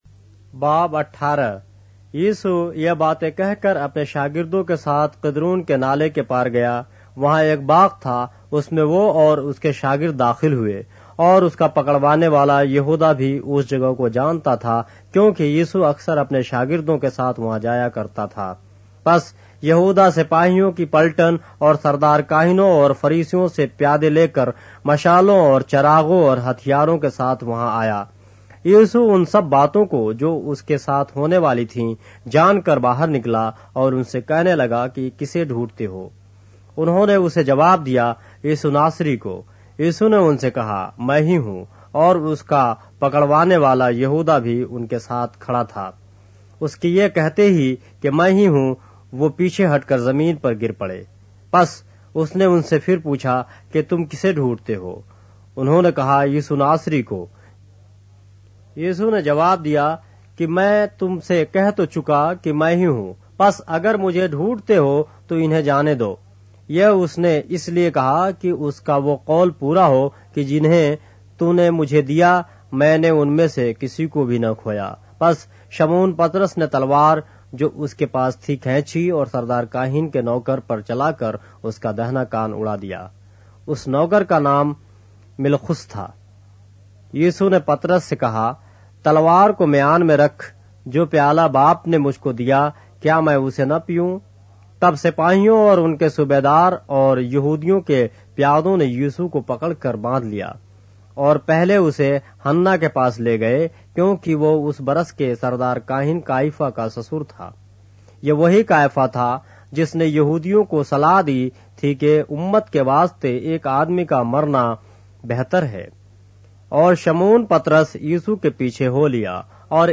اردو بائبل کے باب - آڈیو روایت کے ساتھ - John, chapter 18 of the Holy Bible in Urdu